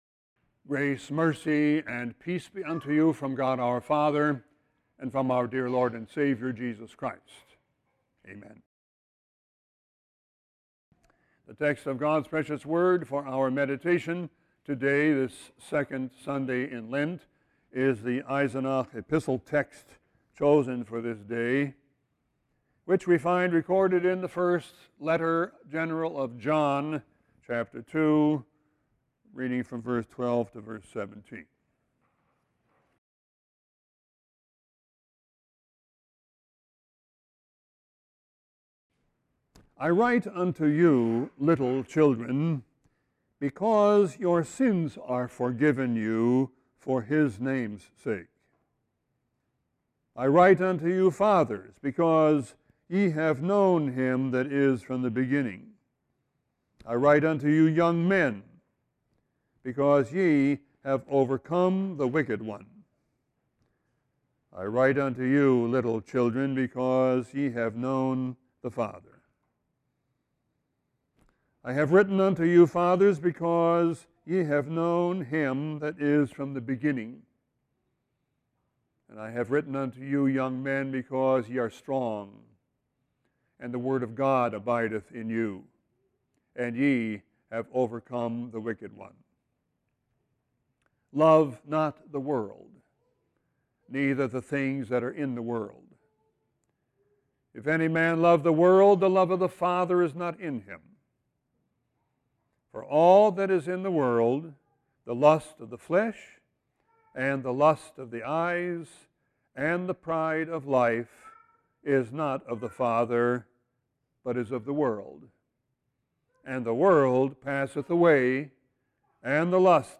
Sermon 2-21-16.mp3